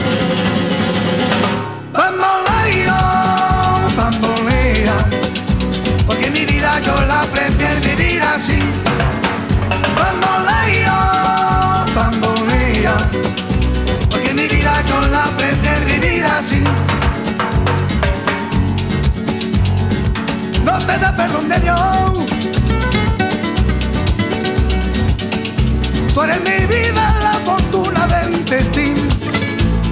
• Contemporary Latin